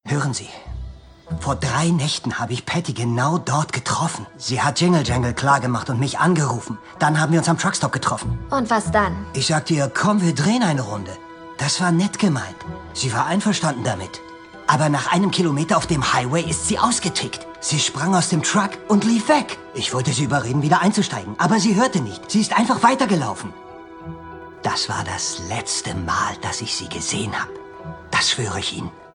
Doku - Sachtext